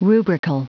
Prononciation du mot rubrical en anglais (fichier audio)
Prononciation du mot : rubrical